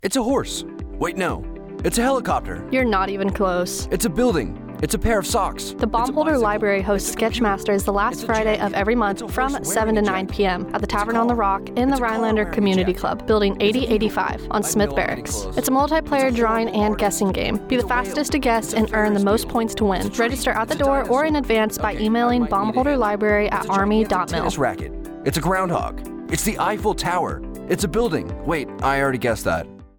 This is a 30-second radio spot on Sketch Masters hosted by Army Family and MWR in Baumholder, Germany, March 5, 2025.